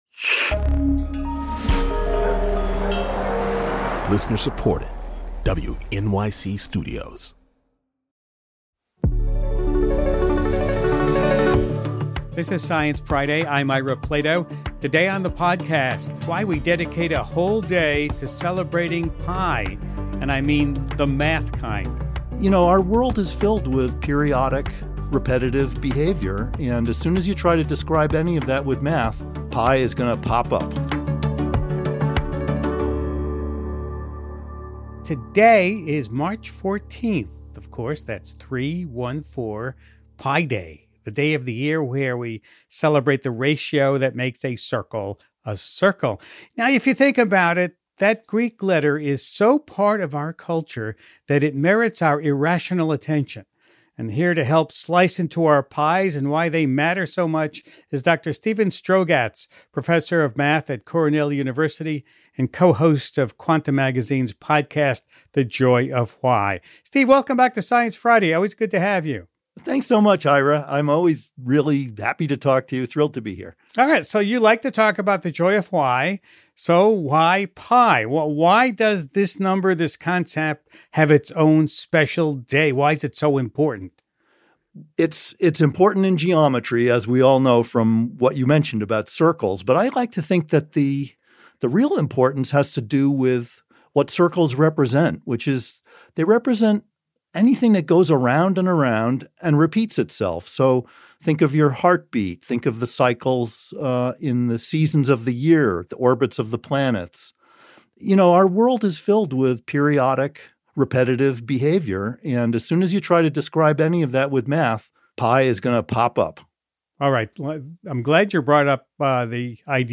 Joining Host Ira Flatow to help slice into our pi’s is Dr. Steven Strogatz, professor of math at Cornell University and co-host of Quanta Magazine ’s podcast “The Joy Of Why.” They talk about how pi was “discovered,” the ways it’s figuring into recent science, and how AI is changing the field of mathematics.